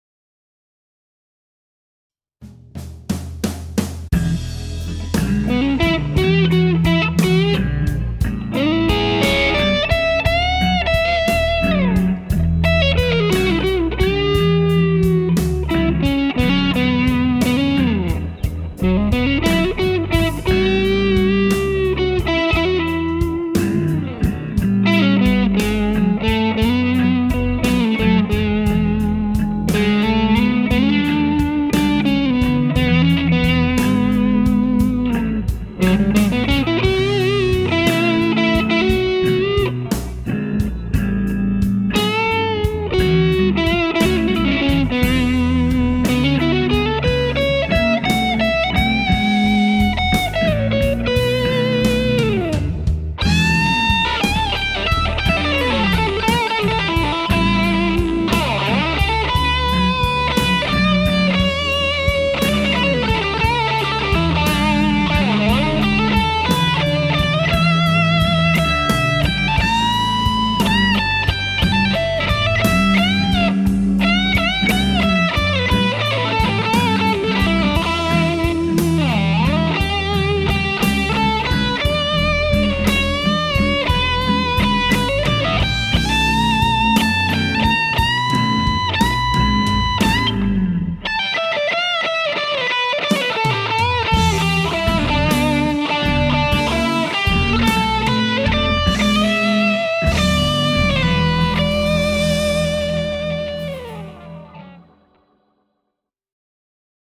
It’s a slow blues in E. The first part of the song is played with the neck pickup of my Prestige Heritage Elite, into my Tone Freak Effects Abunai 2, into my Hardwire RV-7 Reverb, then into the Champ and out my custom Aracom 1 X 12 extension cab with a Jensen P12N (damn! that totally sounds like name dropping!
In second half of the song, I switch to my bridge pickup and stack my Tube Screamer on top of the Abunai 2.
Sorry for the mistakes.
slow_blues.mp3